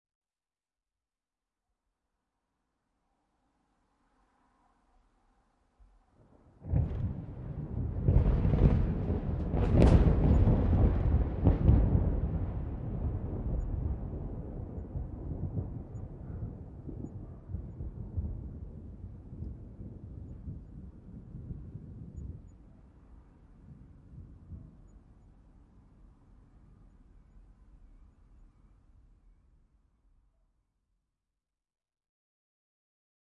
Scary Thunder Sound Button - Free Download & Play